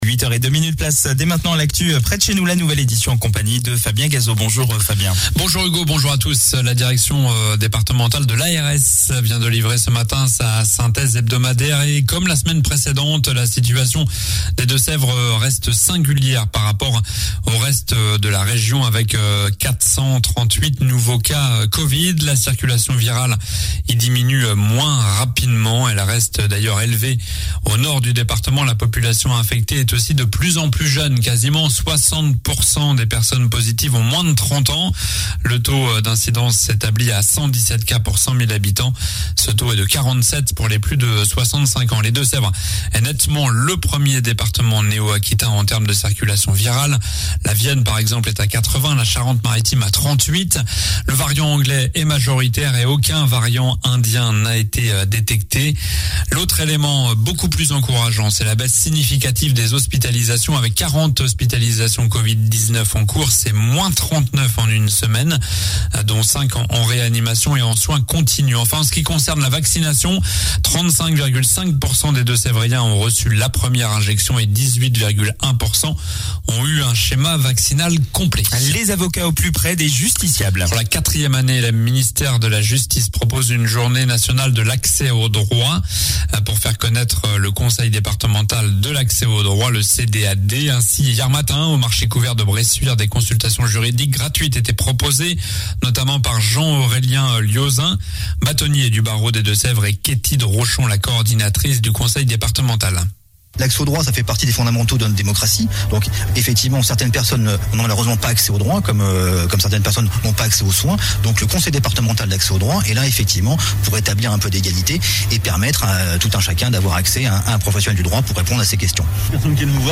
Journal du mercredi 26 mai (matin)